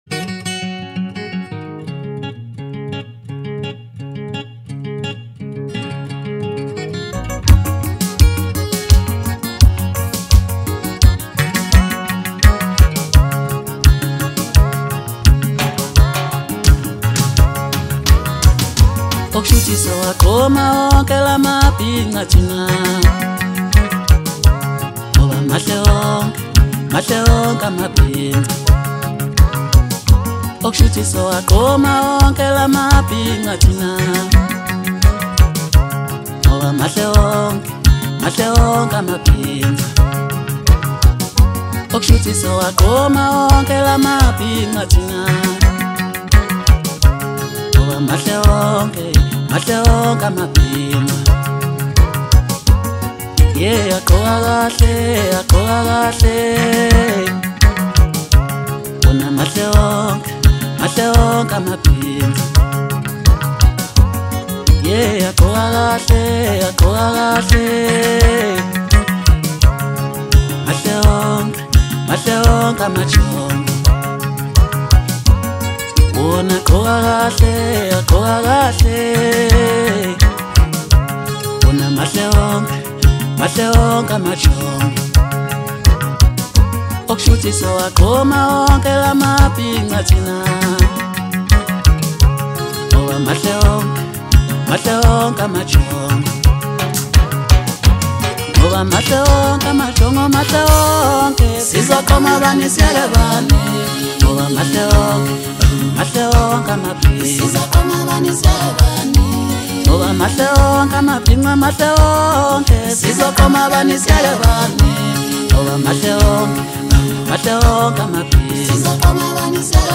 Home » Maskandi » DJ Mix » Hip Hop
emphasizing rich vocals